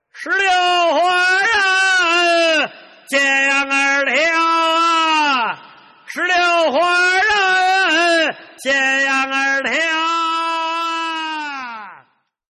老北京吆喝 石榴花 特效人声下载
lao_bei_jing_yao_he-dan_liu_hua-te_xiao_ren_sheng818.mp3